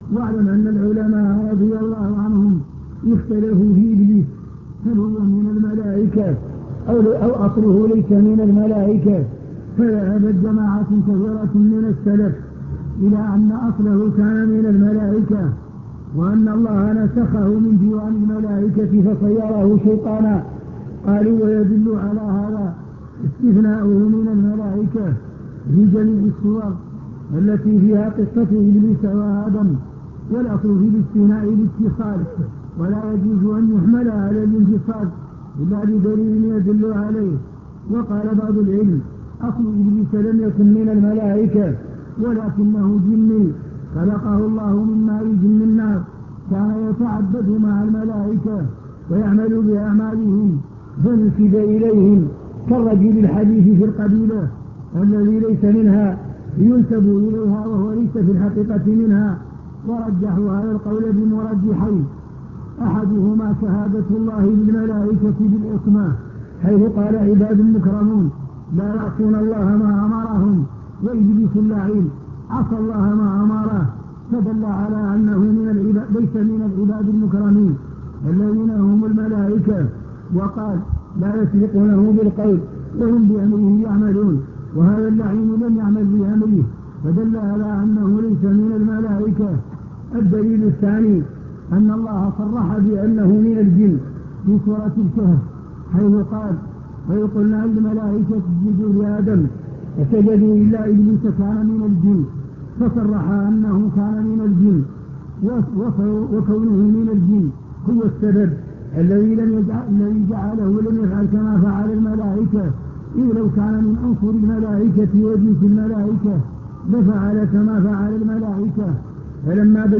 المكتبة الصوتية  تسجيلات - محاضرات ودروس  الرد على ابن حزم